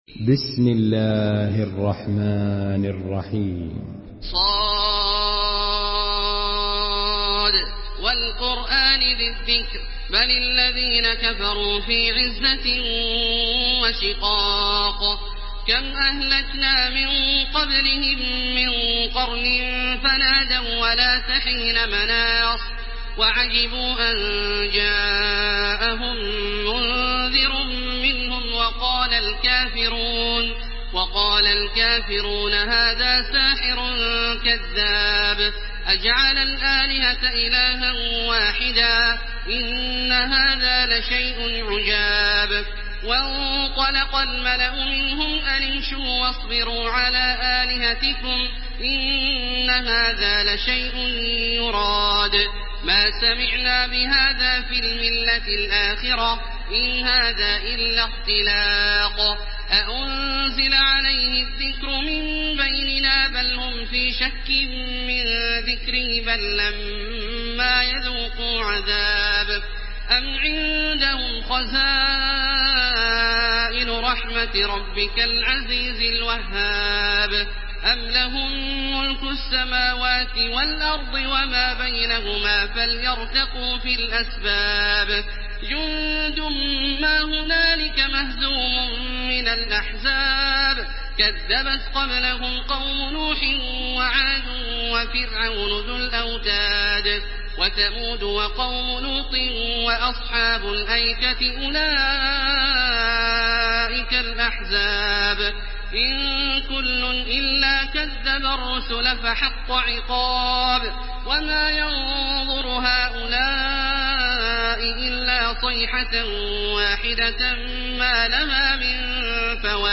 Surah ص MP3 by تراويح الحرم المكي 1430 in حفص عن عاصم narration.